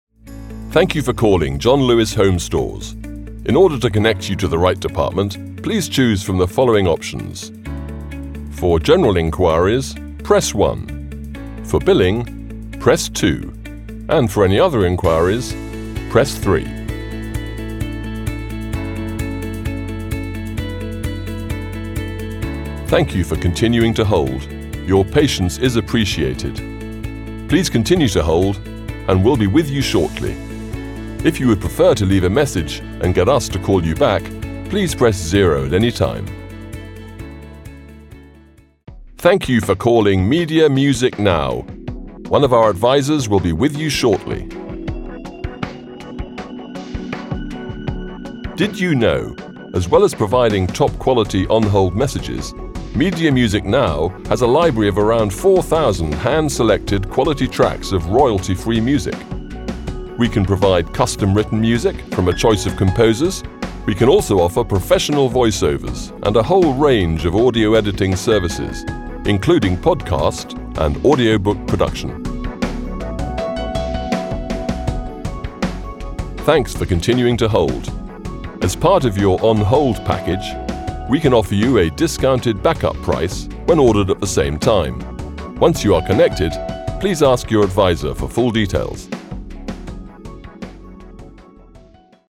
TELEPHONE ON HOLD
MESSAGE ON HOLD